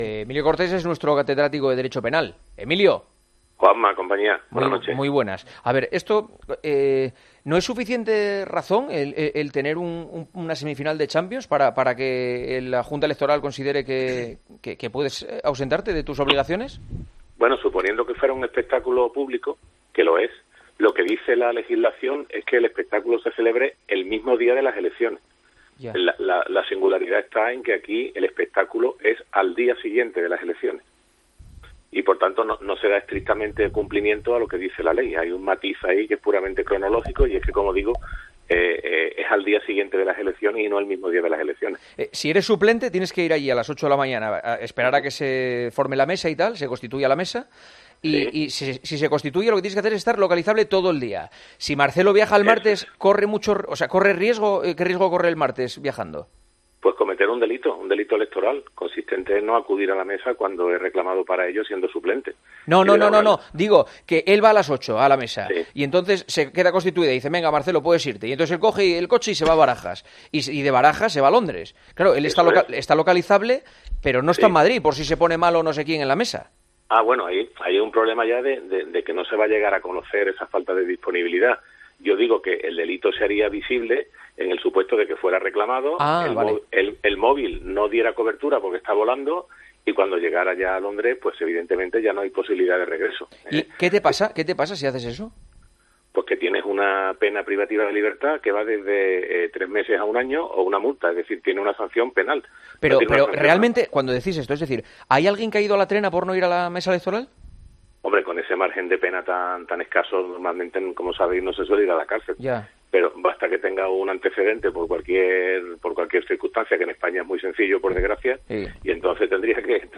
AUDIO: El catedrático de Derecho Penal nos habla de la obligación de Marcelo de presentarse a la mesa de las elecciones al haber sido elegido como suplente...